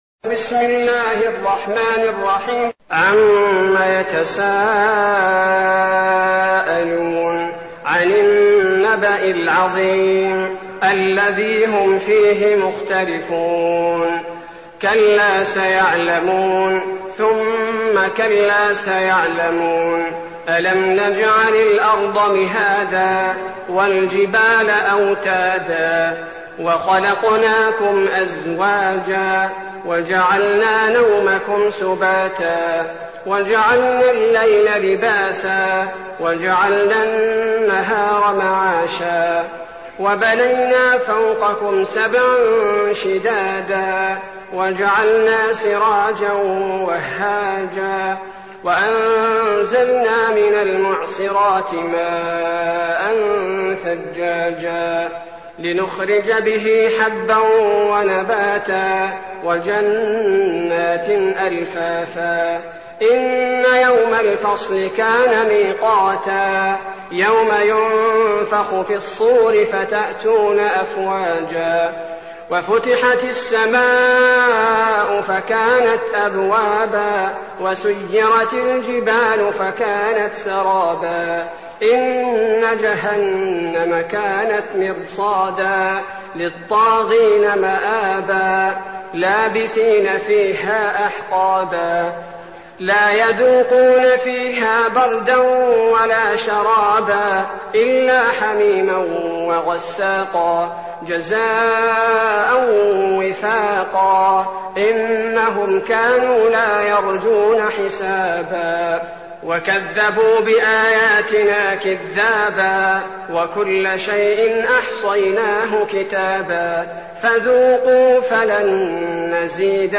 المكان: المسجد النبوي الشيخ: فضيلة الشيخ عبدالباري الثبيتي فضيلة الشيخ عبدالباري الثبيتي النبأ